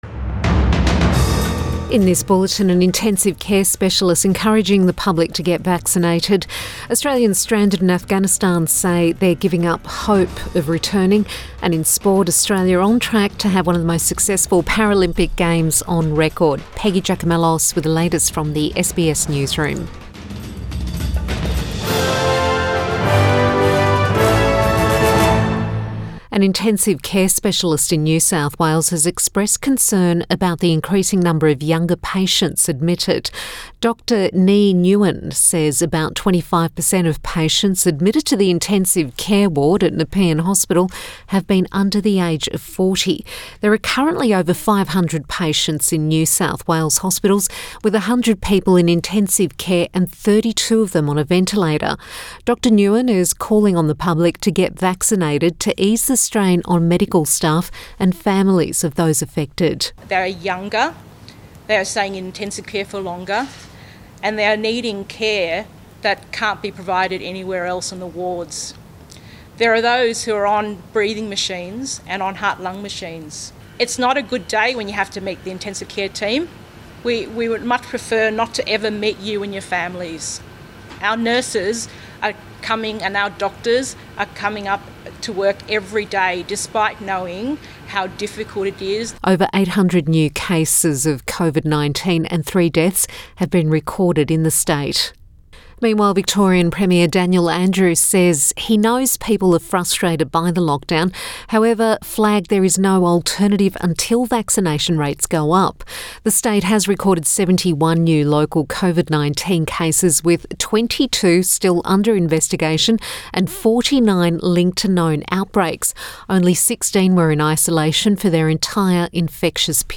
PM bulletin 23 August 2021